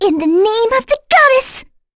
Frey-Vox_Skill2b.wav